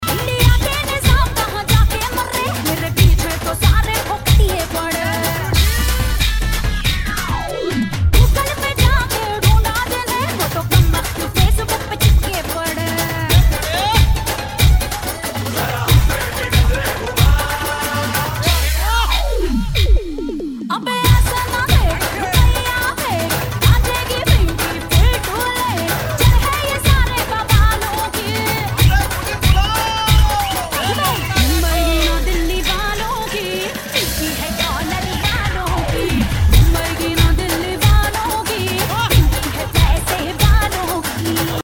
Dandiya Mix Ringtones